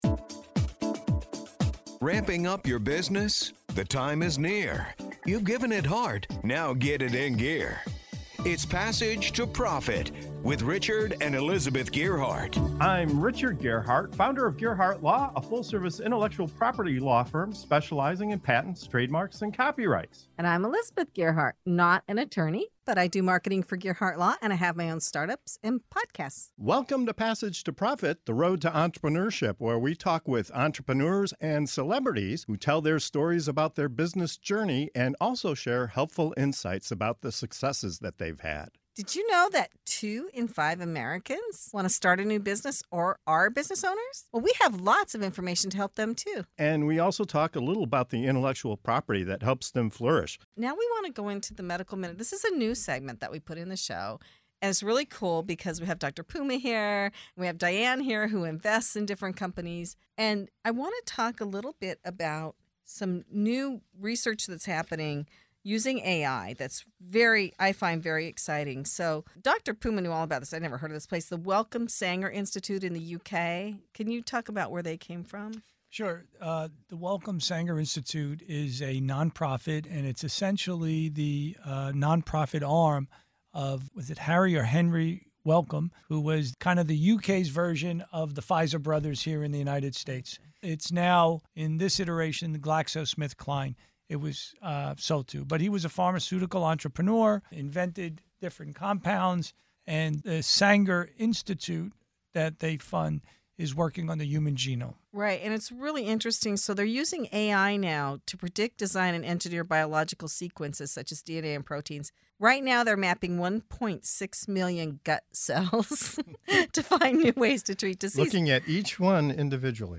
Discover how AI is mapping the human gut, revolutionizing diagnostics, and unlocking new therapies for diseases like cancer and sickle cell. Our panel explores the ethical dilemmas of data privacy, the promise of targeted treatments, and the limitless potential of AI to reshape healthcare. Tune in for a fascinating discussion on the future of medicine and technology!